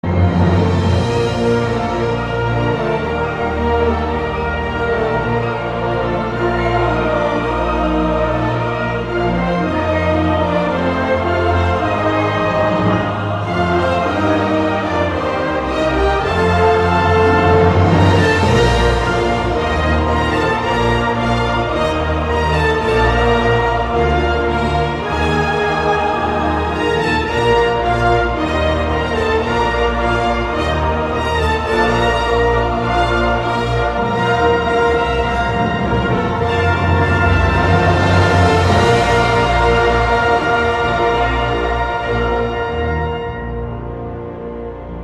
Filmmusik